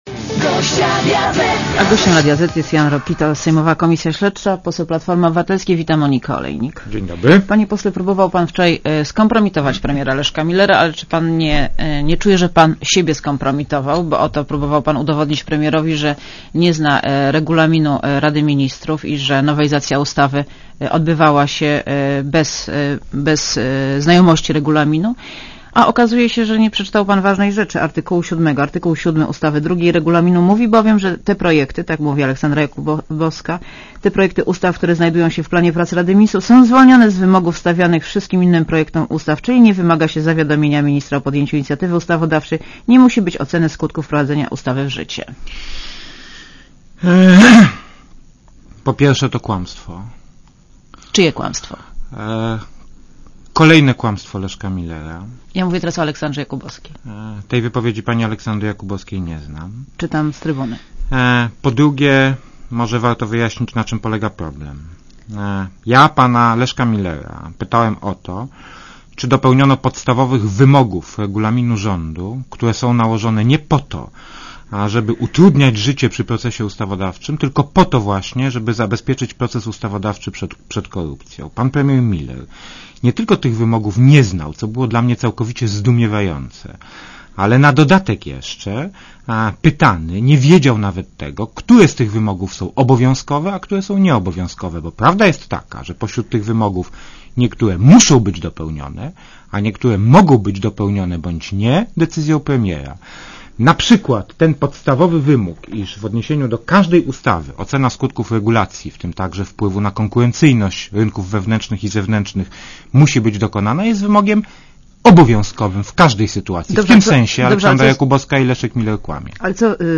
© (RadioZet) Posłuchaj wywiadu (2,6 MB) Panie Pośle, próbował pan wczoraj skompromitować premiera Leszka Millera, ale czy nie czuje pan, że pan siebie skompromitował?